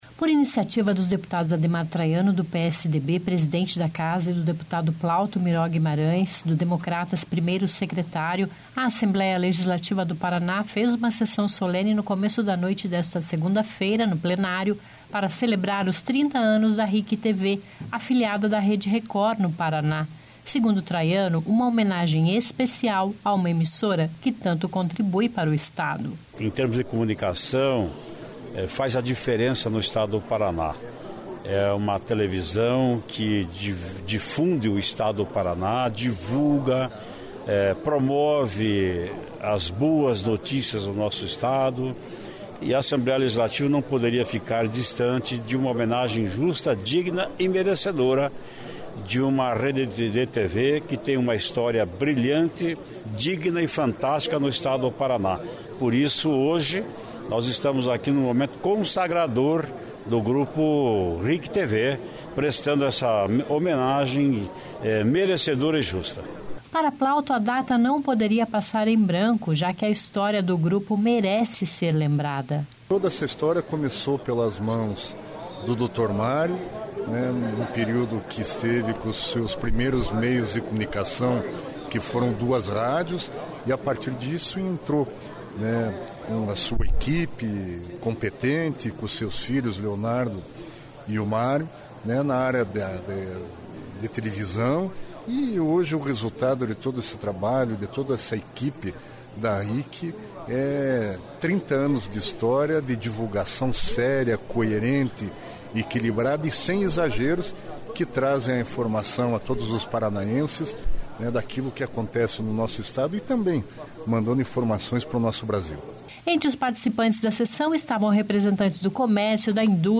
Notícia